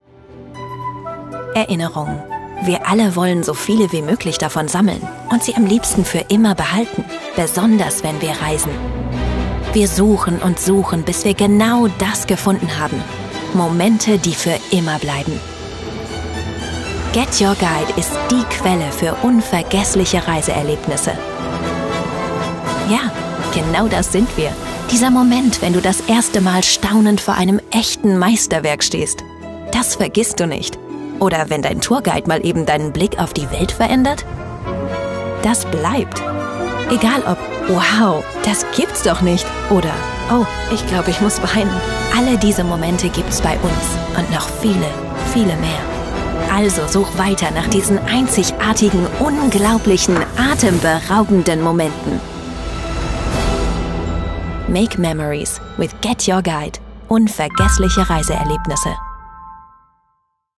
Female
Assured, Authoritative, Character, Confident, Corporate, Engaging, Friendly, Natural, Smooth, Soft, Warm, Versatile
German, Ruhrpott (native)
Microphone: Sennheiser MKH 416